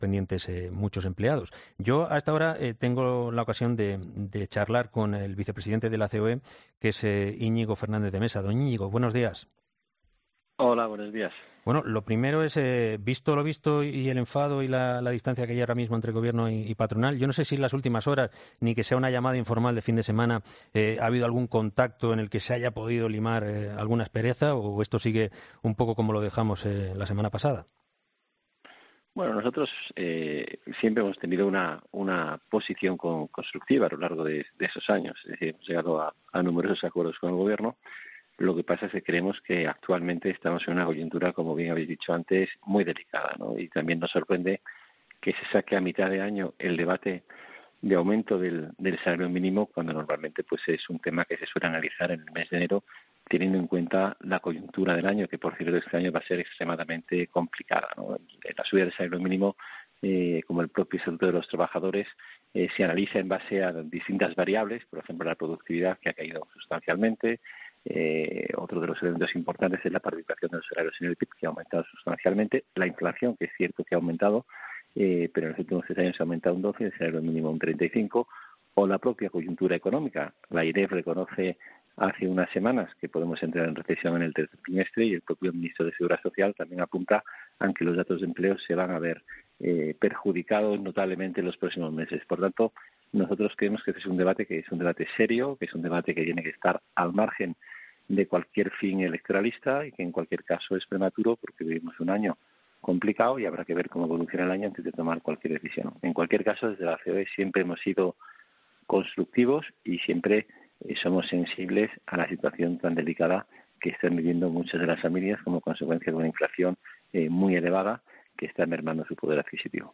Íñigo Fernández de Mesa, vicepresidente de la CEOE, ha pasado por los micrófonos de 'Herrera en COPE' para analizar las últimas noticias que tienen que ver sobre el futuro más inmediato de la Economía y de la propuesta de la vicepresidenta del Gobierno, Yolanda Díaz, para subir una vez más el salario mínimo.